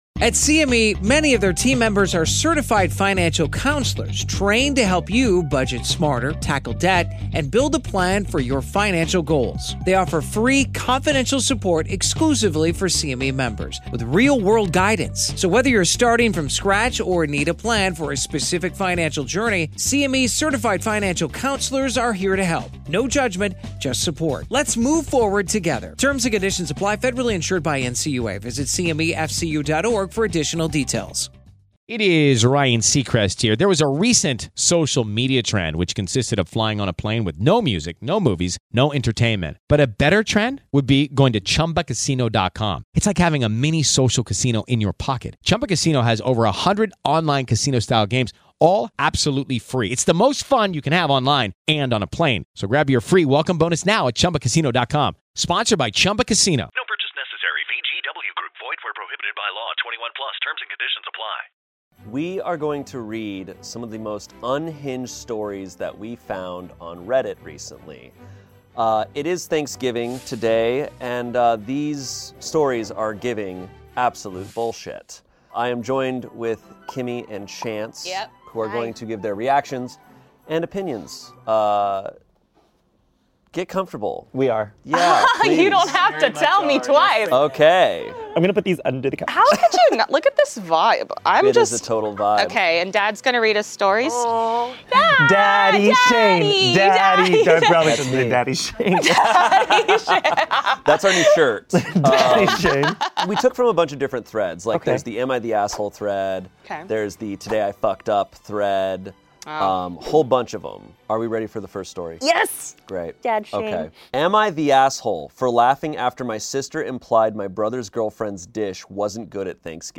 We're reading and reacting to Reddit stories from Am I The Asshole, True Off My Chest, and Petty Revenge that will probably make you feel better about your own family holiday drama!